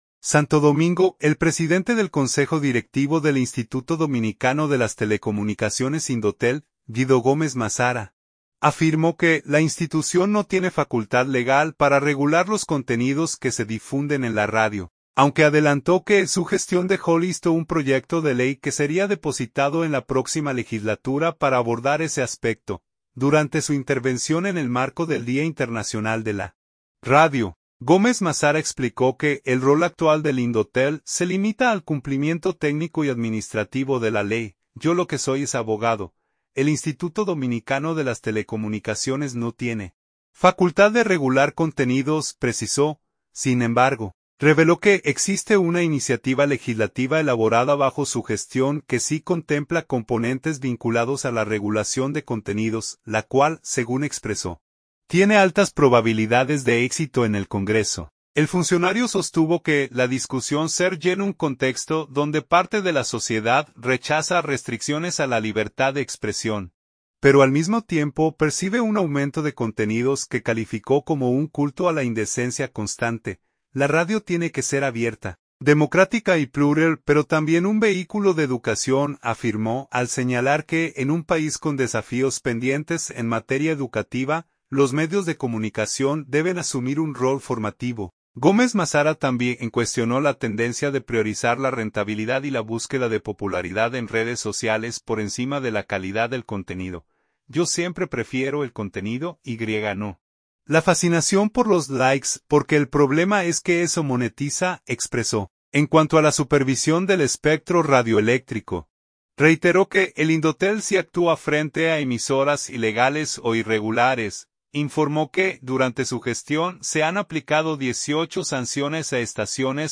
Durante su intervención en el marco del Día Internacional de la Radio, Gómez Mazara explicó que el rol actual del Indotel se limita al cumplimiento técnico y administrativo de la ley.